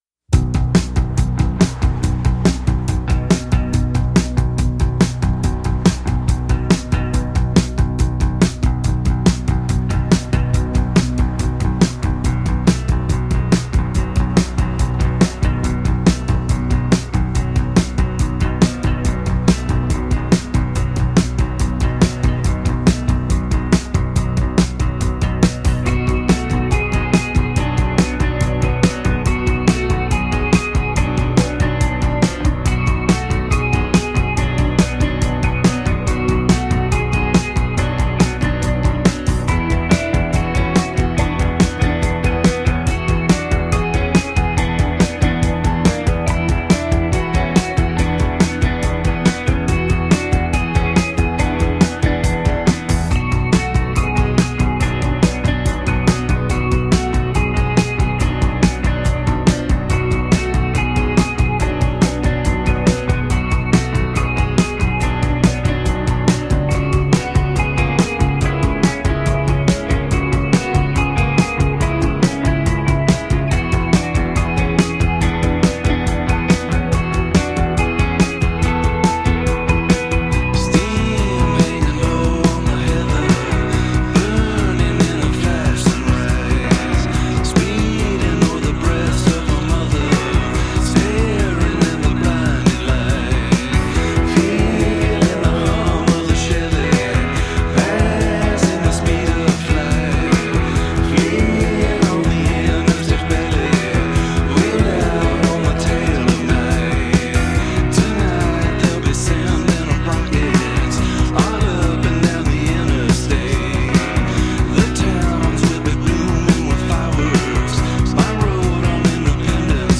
post-punk group